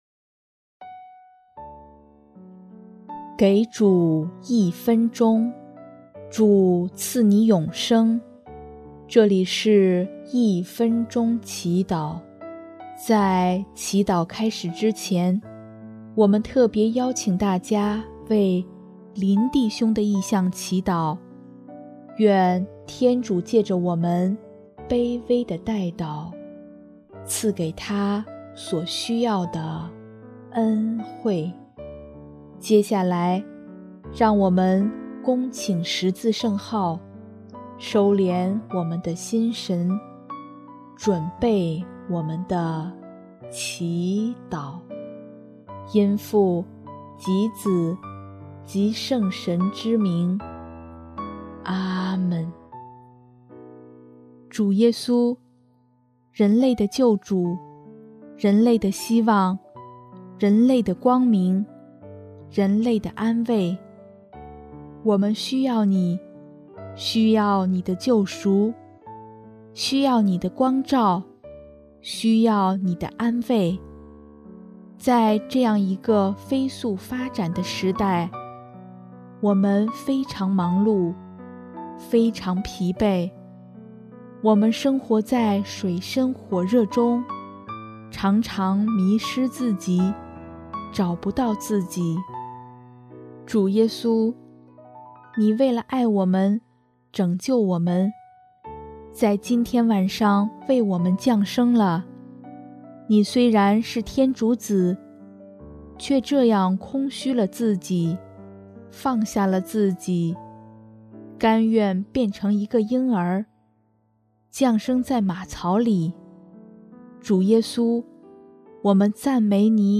【一分钟祈祷】|12月24日 人类的救主，为了我们降生了